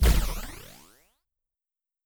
plasma_shotgun_shot.wav